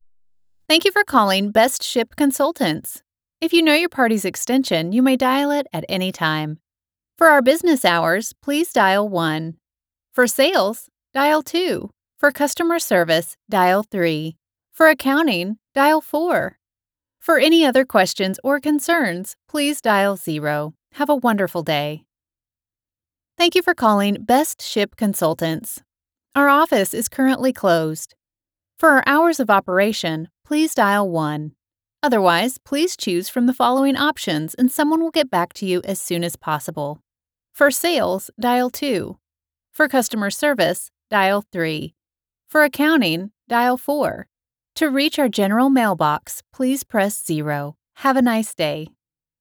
Auto Attendant